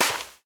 PixelPerfectionCE/assets/minecraft/sounds/step/sand5.ogg at mc116
sand5.ogg